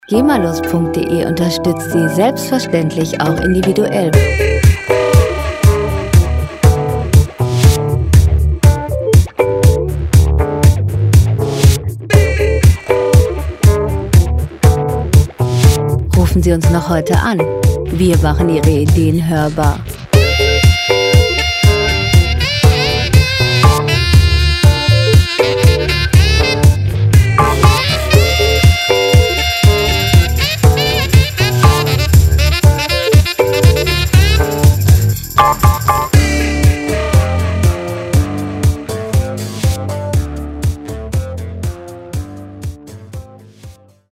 Lounge Musik - Cool und lässig
Musikstil: Neo-Soul
Tempo: 120 bpm
Tonart: D-Moll
Charakter: rotzig, ungewöhnlich
Instrumentierung: E-Piano, Synthesizer, Saxophon, Sprecher